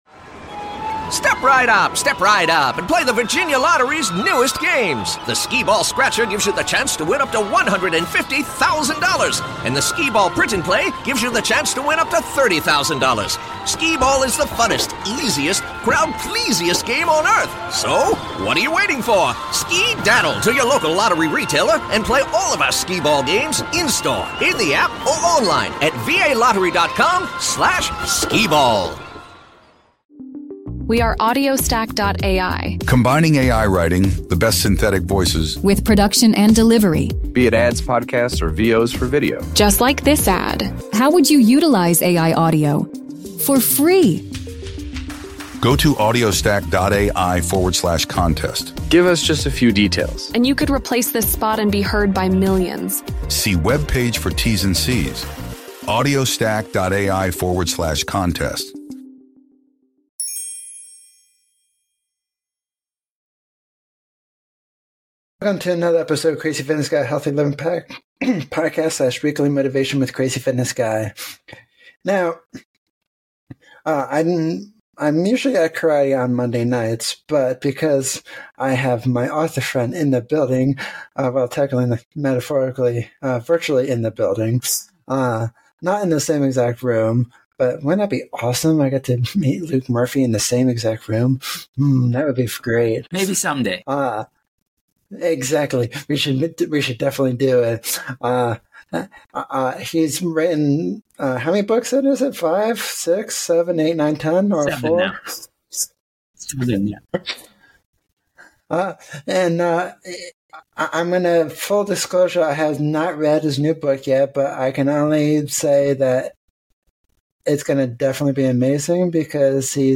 Join us for an exclusive interview that takes you behind the scenes of 'The Cradle Will Fall